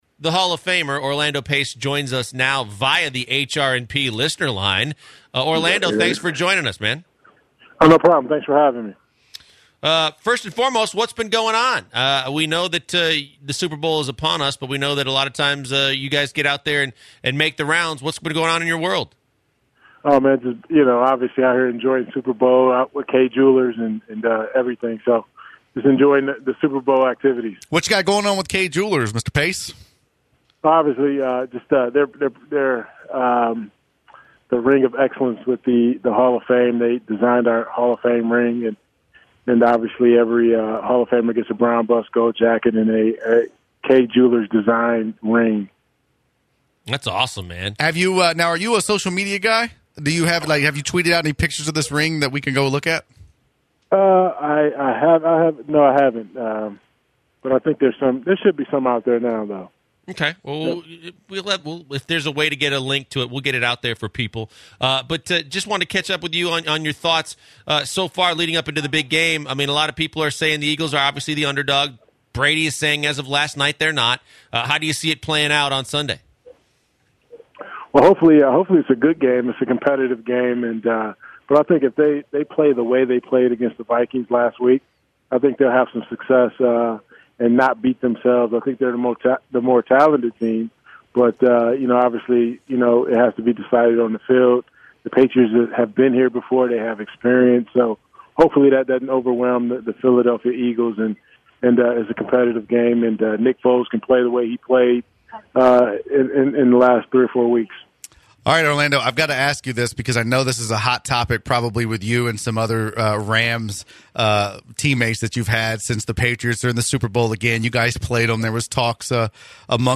Hall of Famer, Orlando Pace, joins the show in the second hour to discuss Super Bowl VII, Michigan State and how he possibly became a Texan.